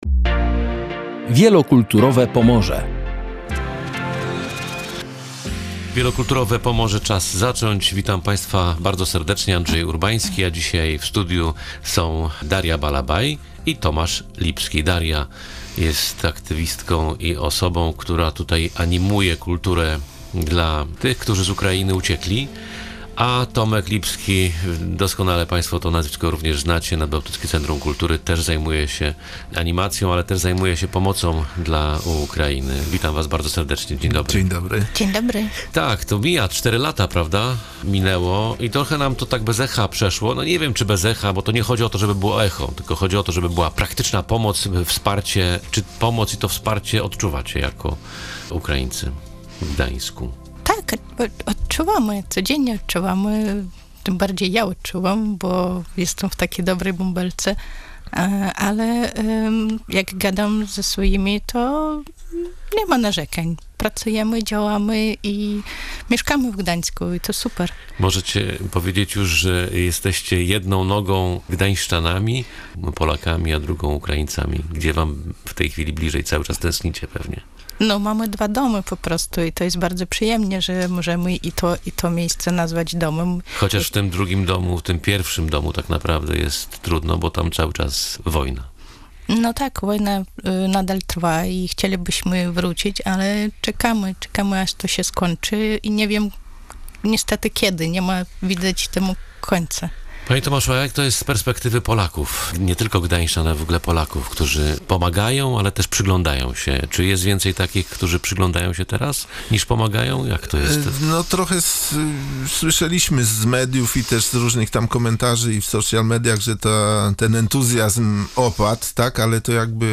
Posłuchaj rozmowy o pomocy i nadziei: